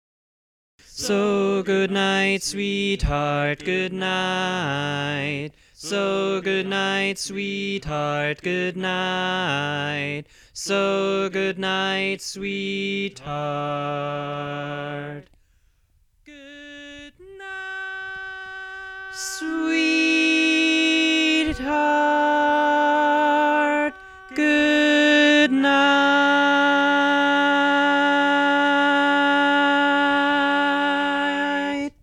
Key written in: G Major
Type: Barbershop
Each recording below is single part only.